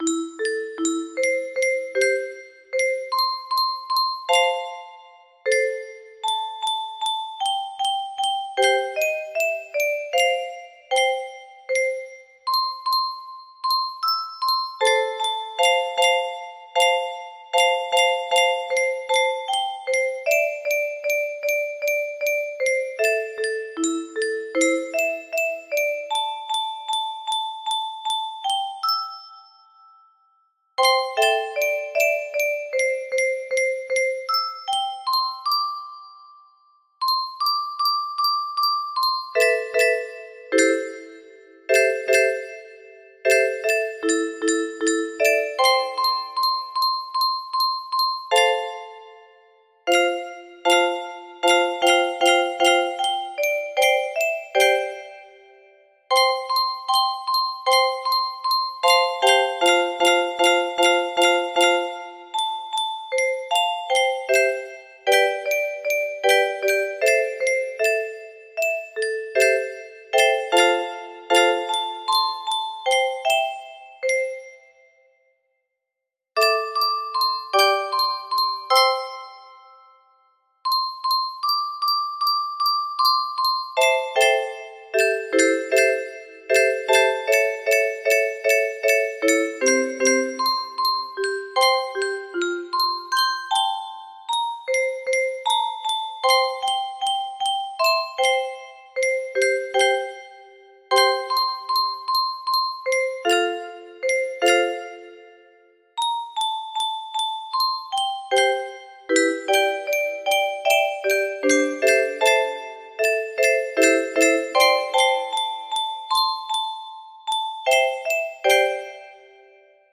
Unknown Artist - Untitled music box melody
Imported from MIDI from imported midi file (7).mid